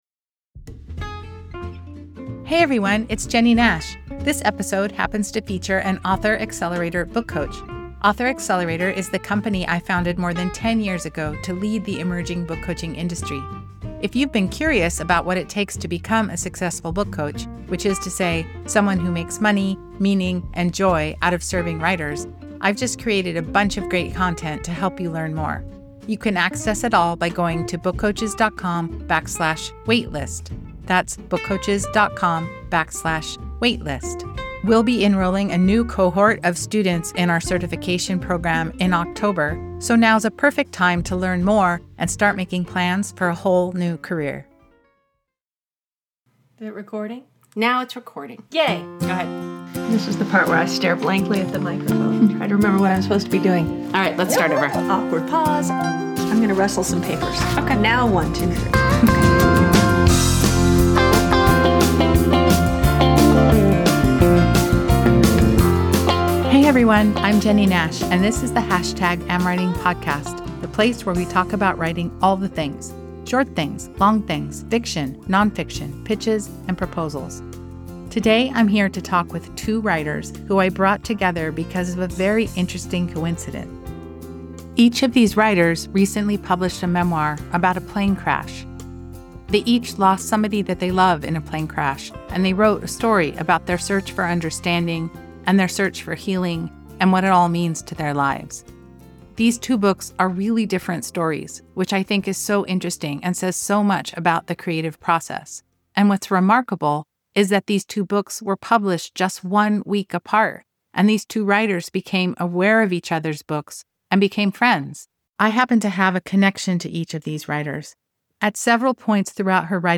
A conversation with two writers whose books about the same kind of tragedy came out the same week